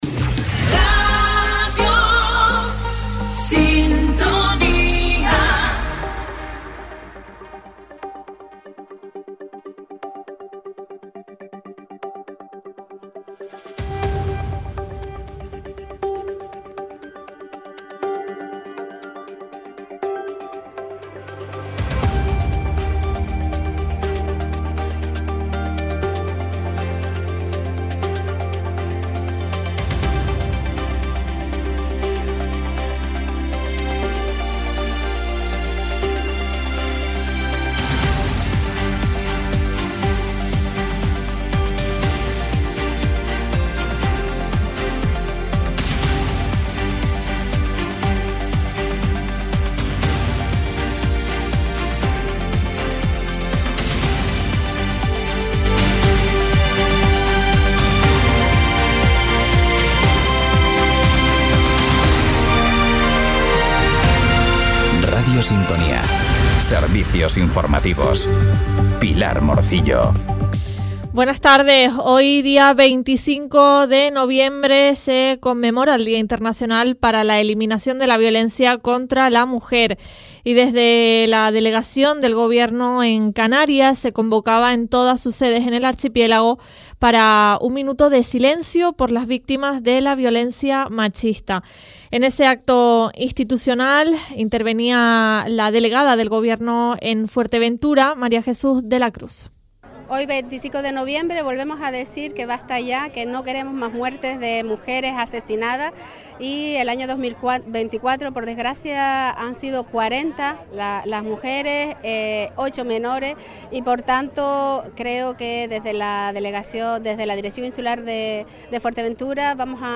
Informativos en Radio Sintonía